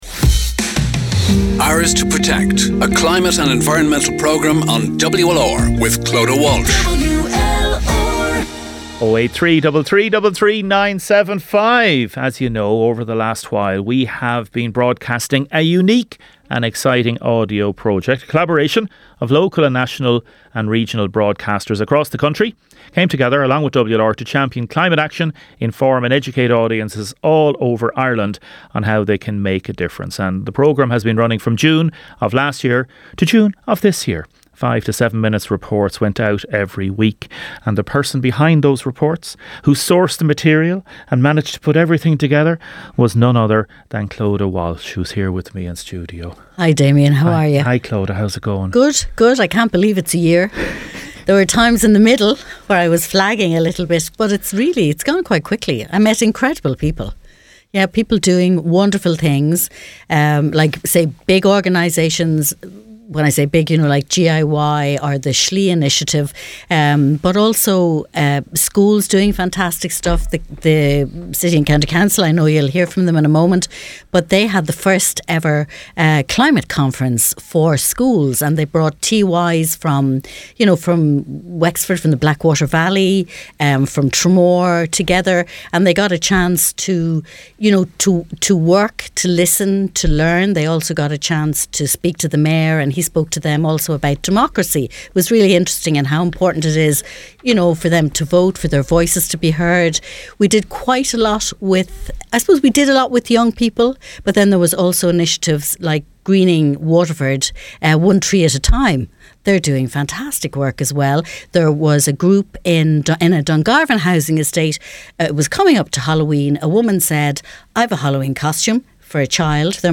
in studio for a round table chat